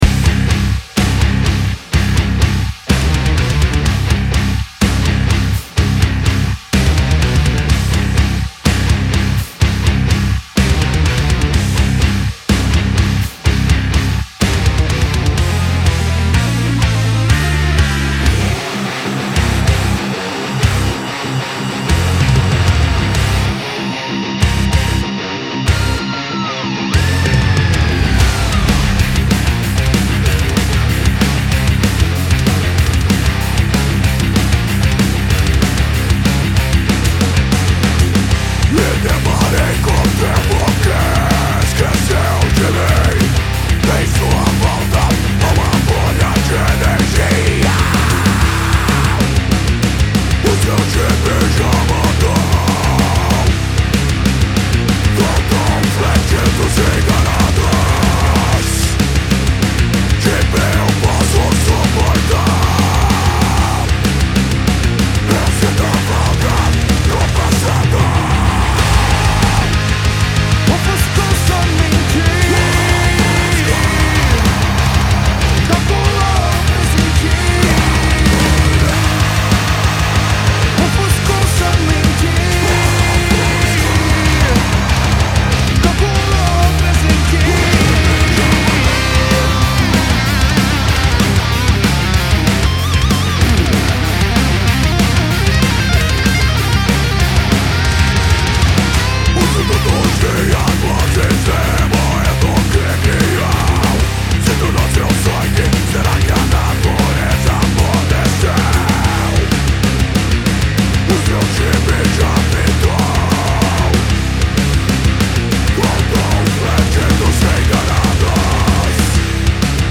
EstiloMetal Melódico